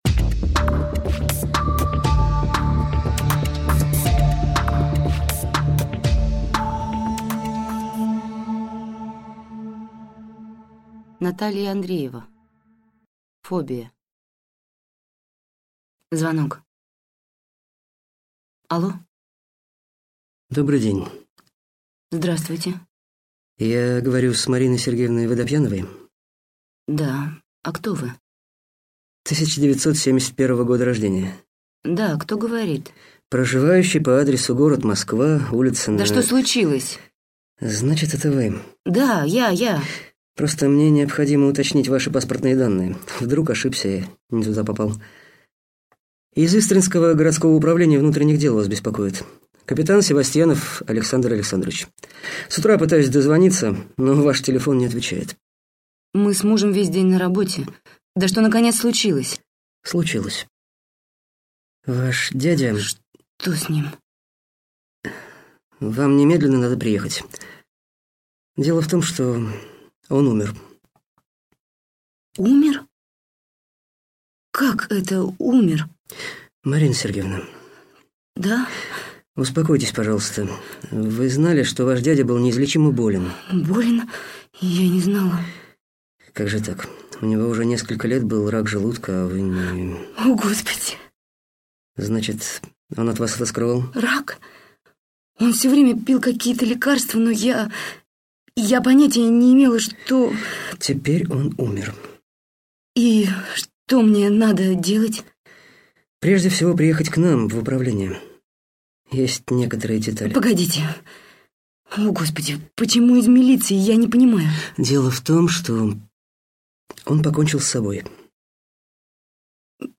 Аудиокнига Фобия | Библиотека аудиокниг